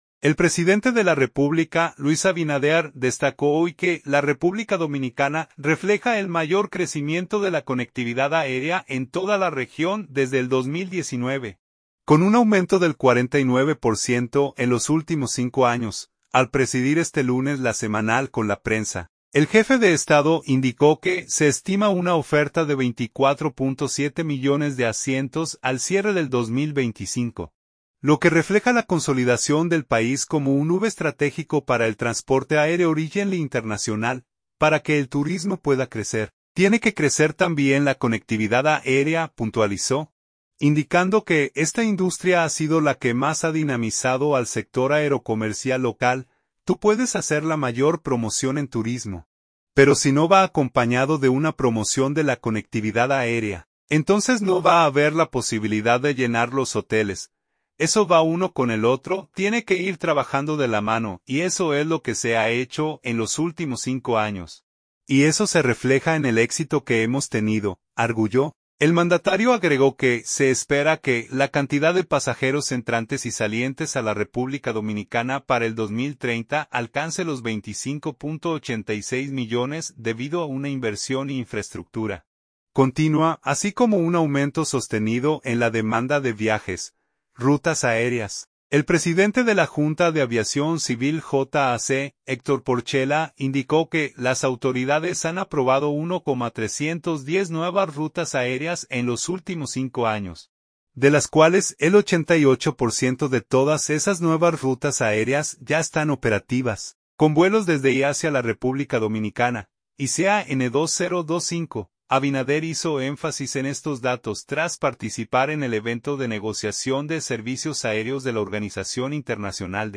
Al presidir este lunes LA Semanal con la prensa, el jefe de Estado indicó que se estima una oferta de 24.7 millones de asientos al cierre del 2025, lo que refleja la consolidación del país "como un hub estratégico" para el transporte aéreo regional e internacional.